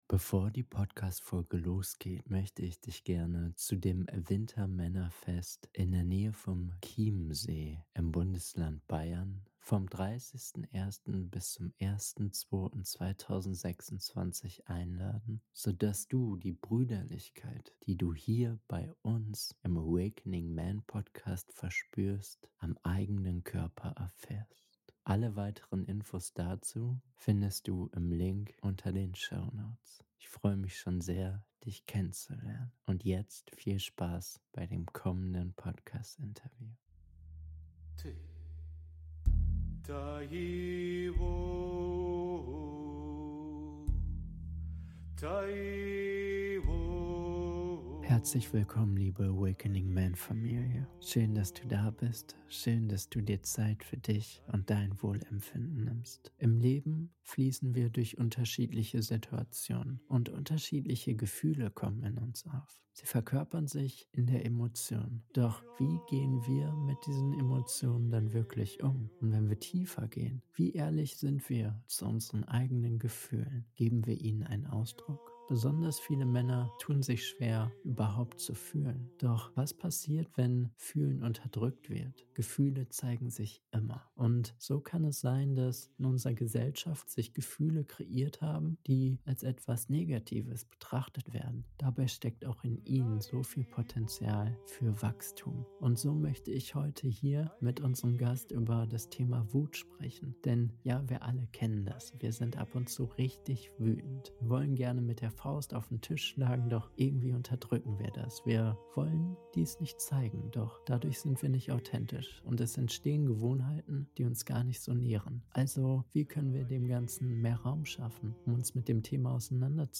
Wie geht es dir wirklich? - Interview